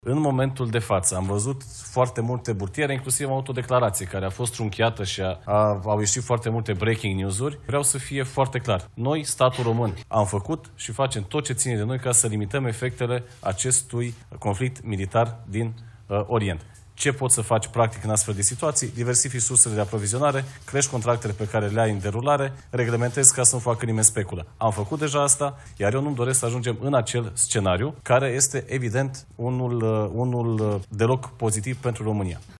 Ministrul Energiei, Bogdan Ivan: „Am făcut și facem tot ce ține de noi pentru a limita efectele acestui conflict militar din Orient”